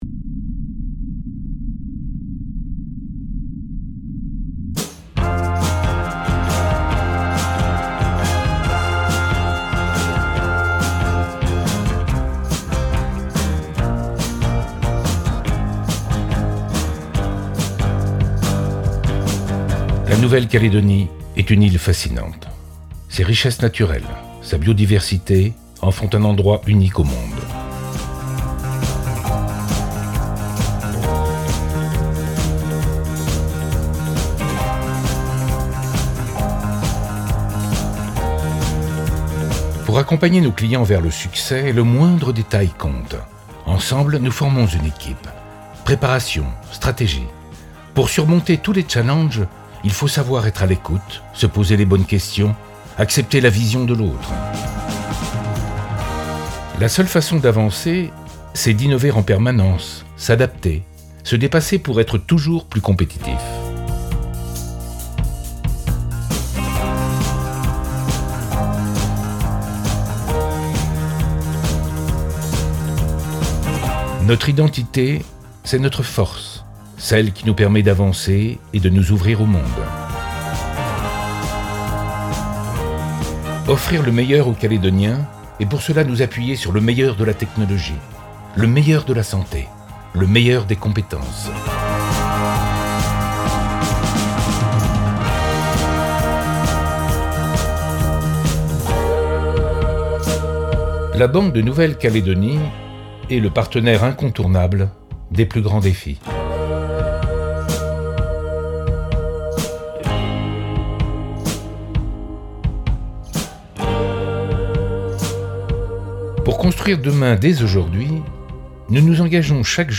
Voix-off pour convention